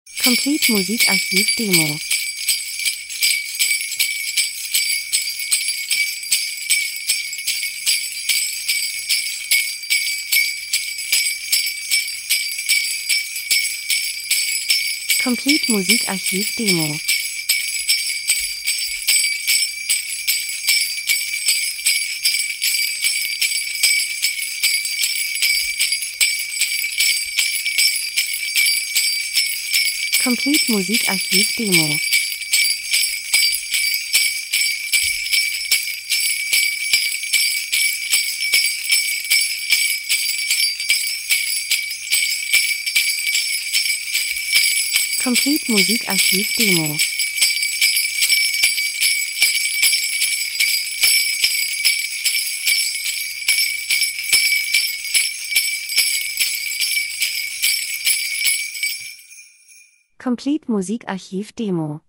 Winter - Fahrt auf dem Schlitten Glöckchen 01:02